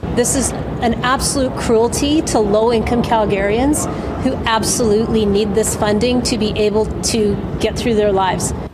Calgary Mayor Jyoti Gondek spoke with Media late Tuesday.